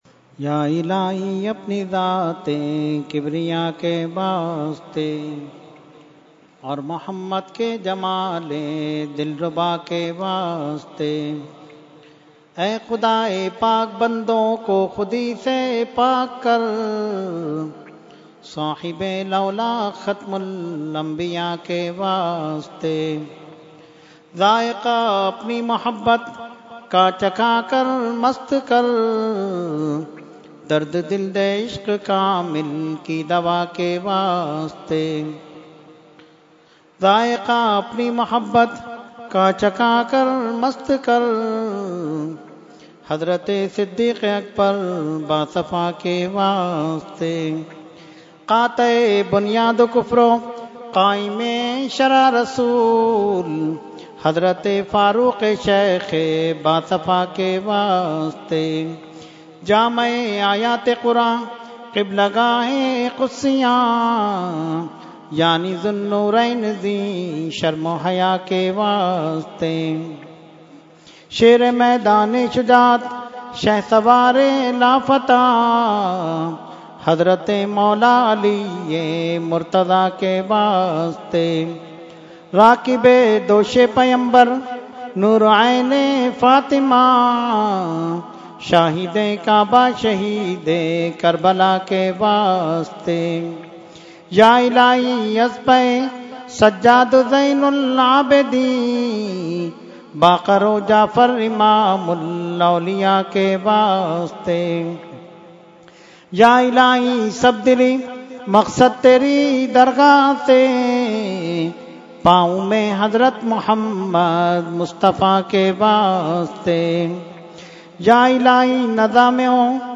Dua – Subah Baharan 2018 – Dargah Alia Ashrafia Karachi Pakistan